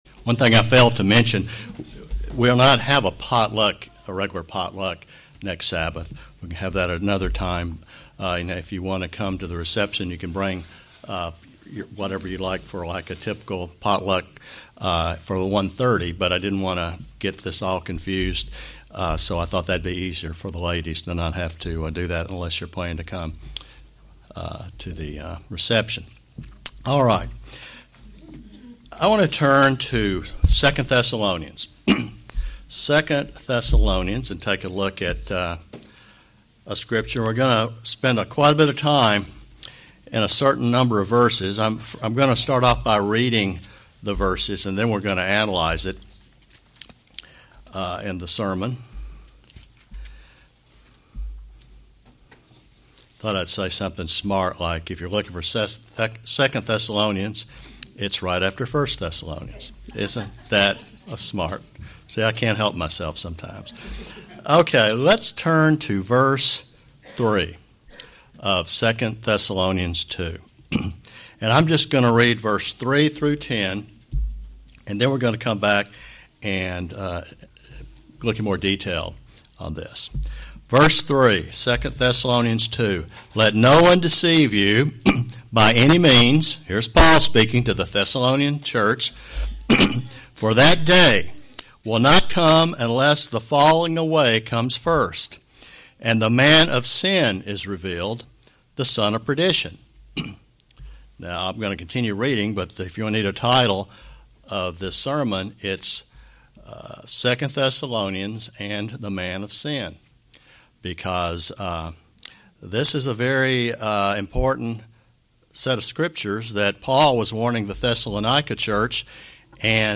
Sermons
Given in Ocala, FL